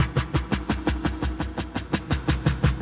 Index of /musicradar/rhythmic-inspiration-samples/85bpm
RI_DelayStack_85-05.wav